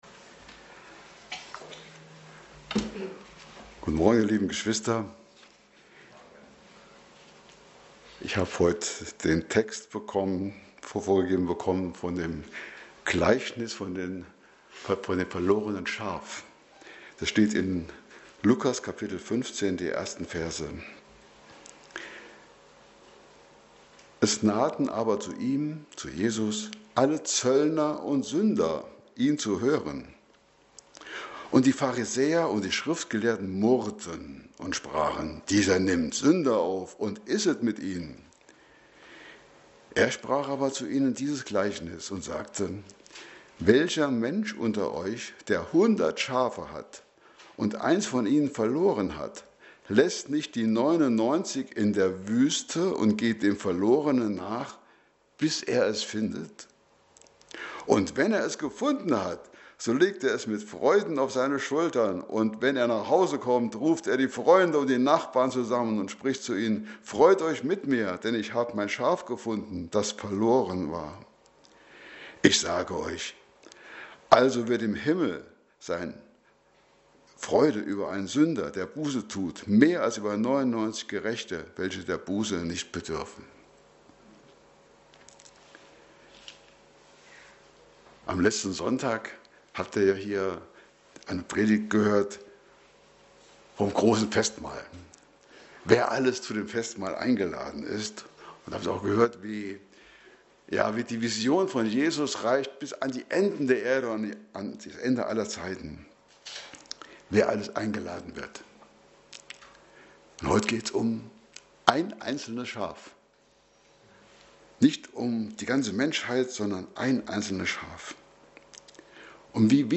Lukas-Evangelium Passage: Lukas 15,1-7 Dienstart: Predigt Themen